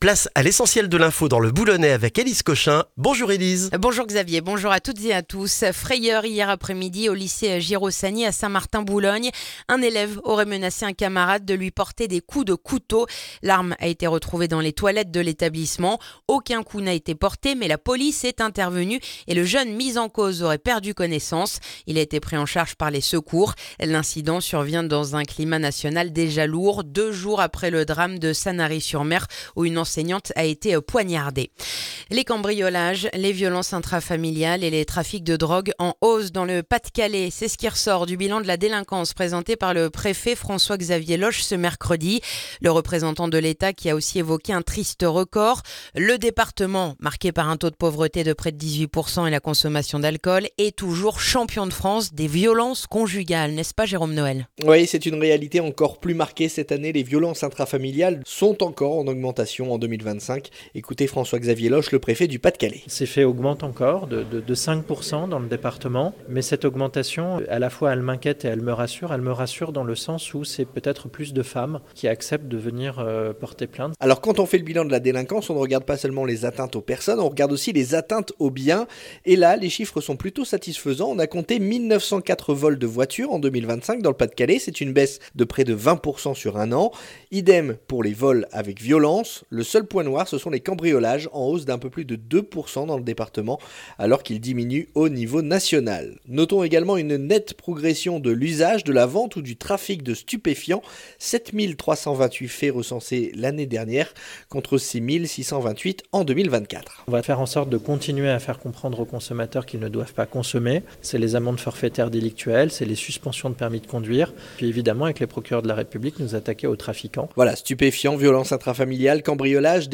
Le journal du vendredi 6 février dans le boulonnais